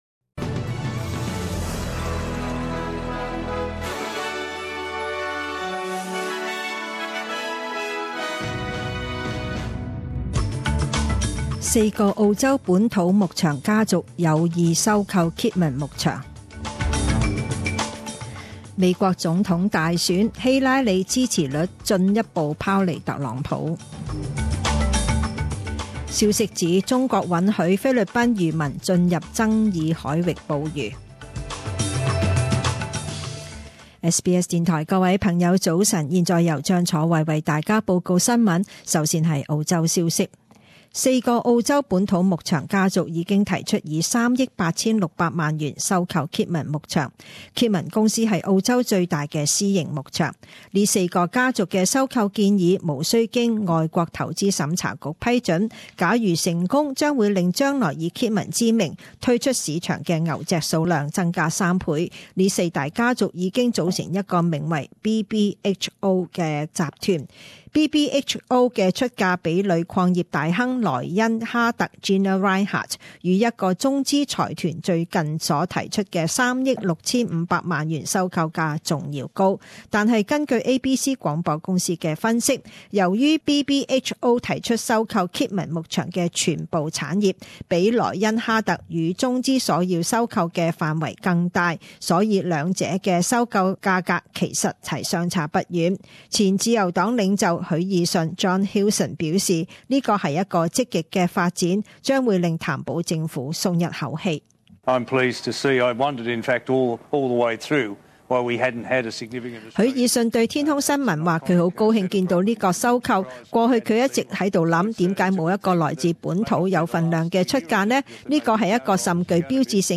详尽早晨新闻